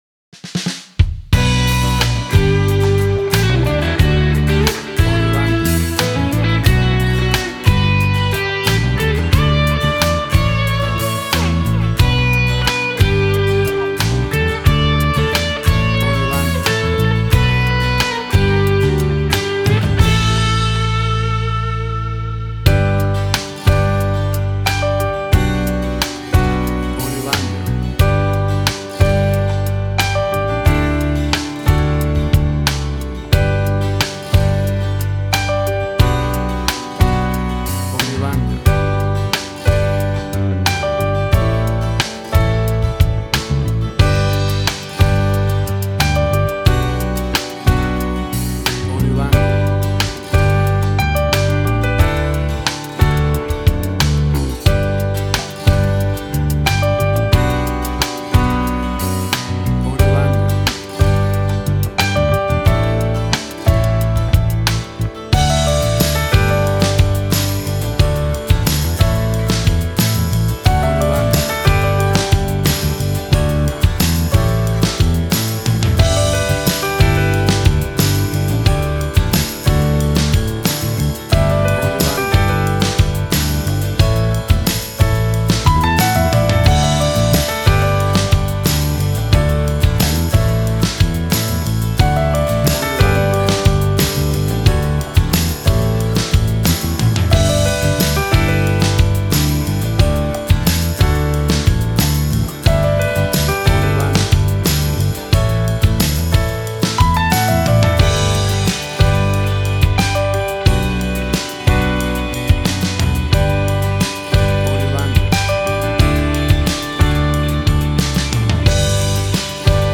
Tempo (BPM): 90